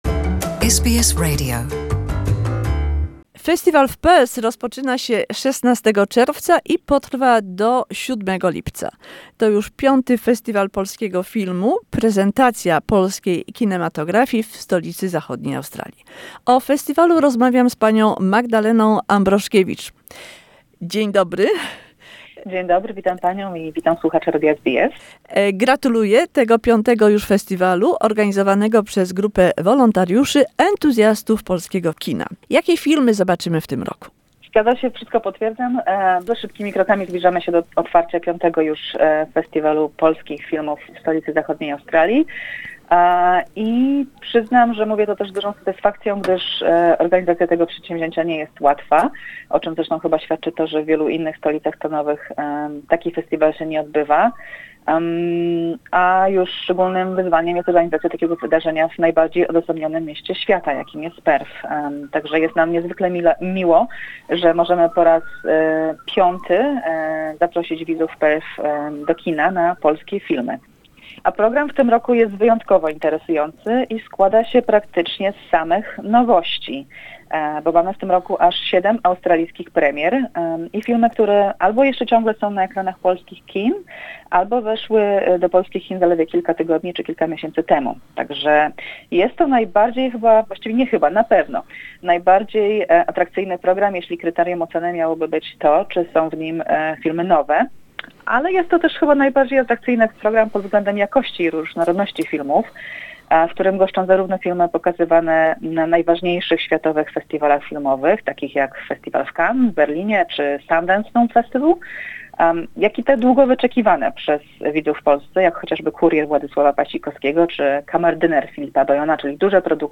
The Polish Film Festival in Perth starts 16 June till 7 July showcases the best of Polish cinema. Conversation